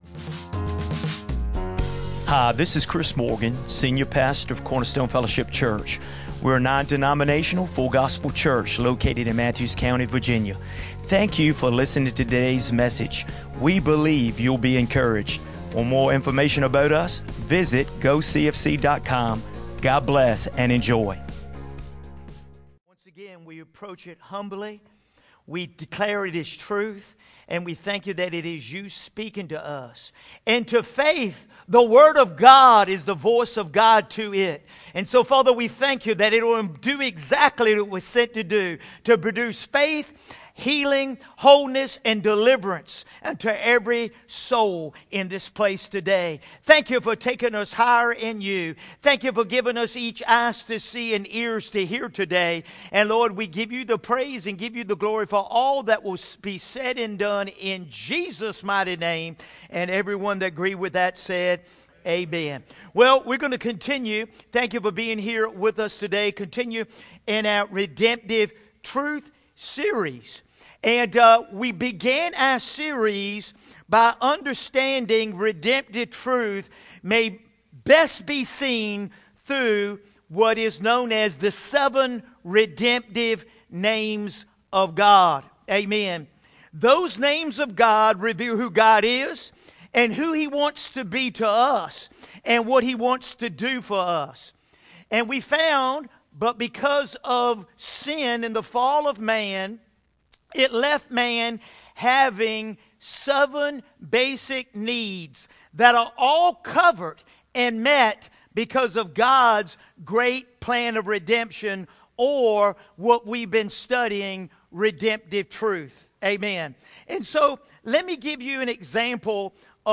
Learn how Christ’s victory on the cross has defeated Satan’s power over believers, and discover how you can walk in divine healing, peace, and righteousness today. This sermon dives into scriptures such as Genesis 3:15, Colossians 2:15, and more to show how God’s redemptive plan restores what was lost in the fall and provides victory for every believer.